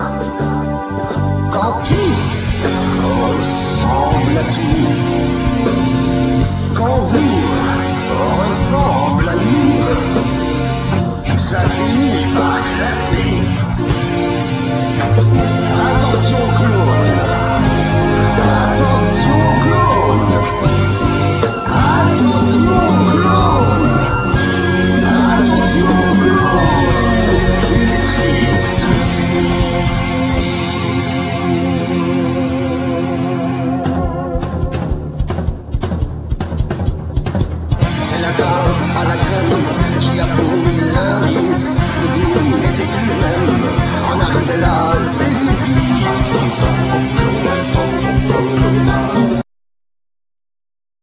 Violin,Vocals
Keyboards,Backing vocals
Drums,Percussions
Guitar,Vocals
Bass